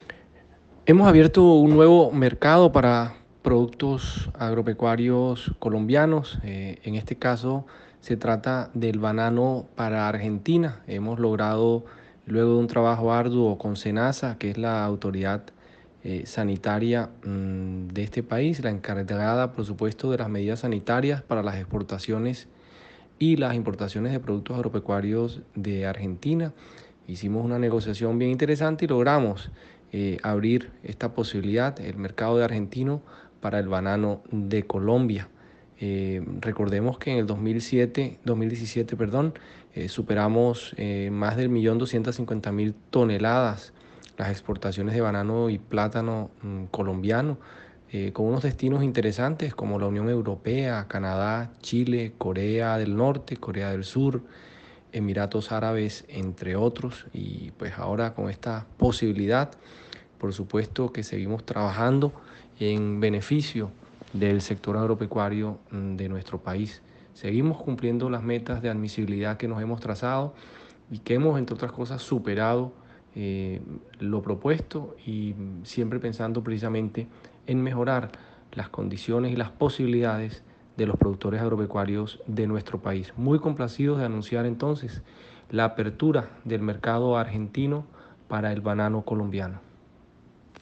• Descargue declaraciones del gerente general del ICA: